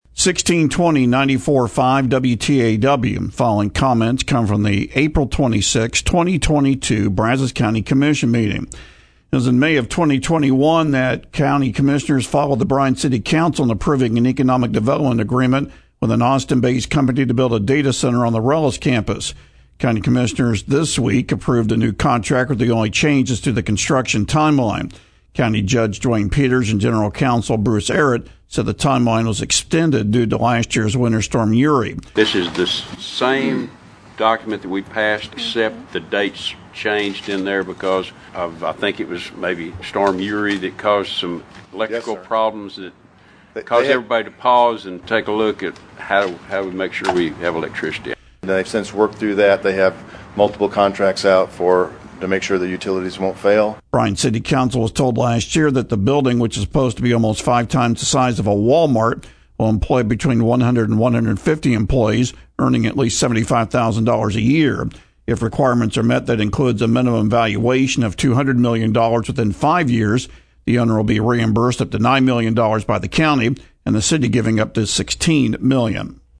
County commissioners during Tuesday’s meeting approved a new contract where the only change is to the construction timeline.